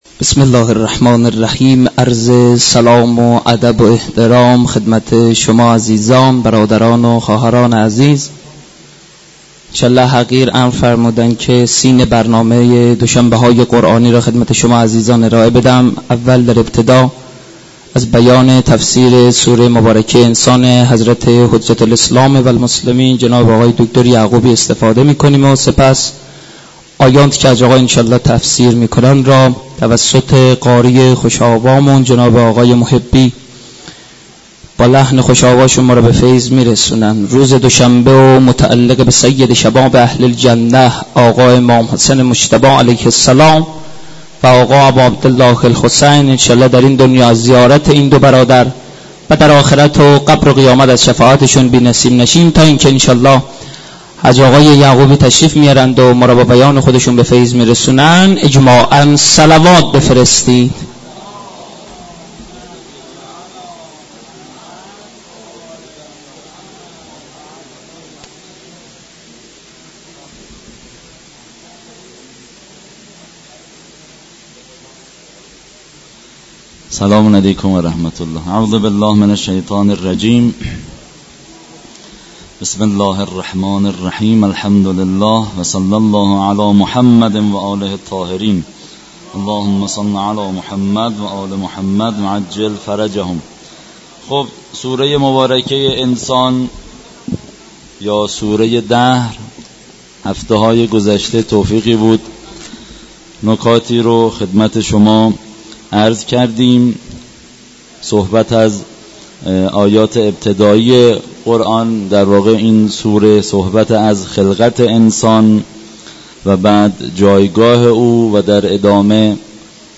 مراسم معنوی دوشنبه های قرآنی در مسجد دانشگاه کاشان برگزار شد.